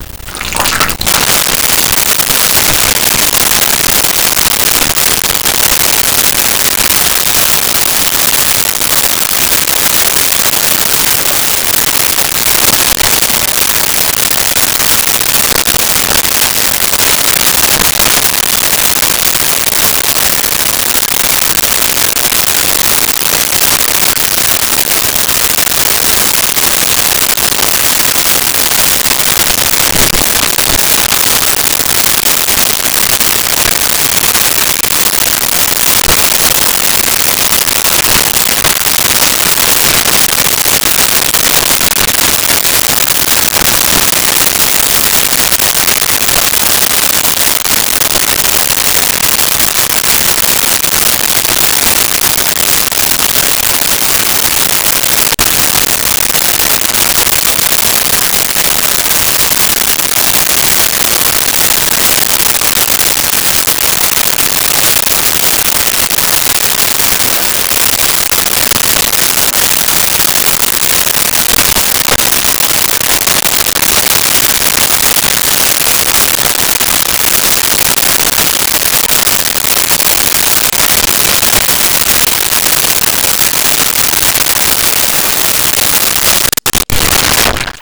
Bathing Washing Scrubbing
Bathing Washing Scrubbing.wav